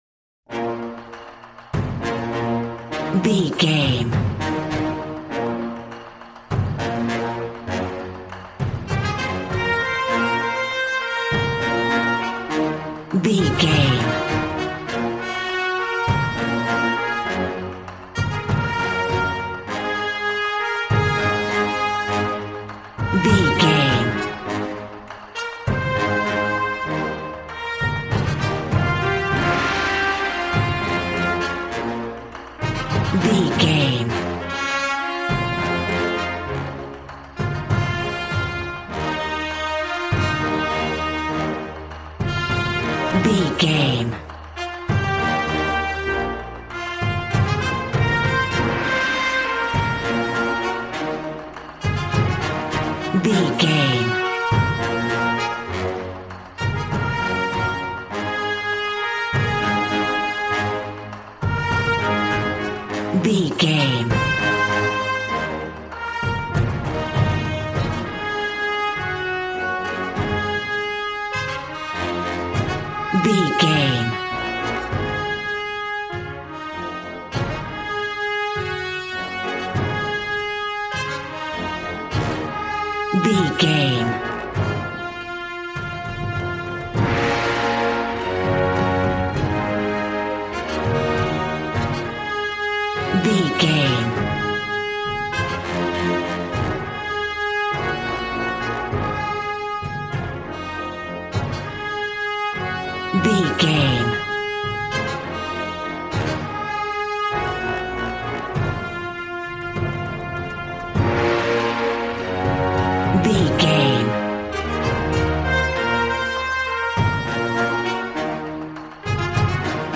Action and Fantasy music for an epic dramatic world!
Ionian/Major
A♭
hard
groovy
drums
bass guitar
electric guitar